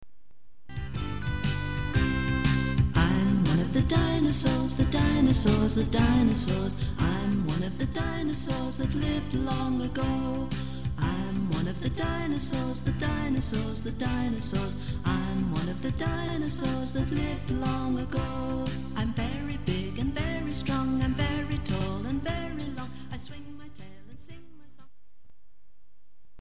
CHILDREN'S SONGS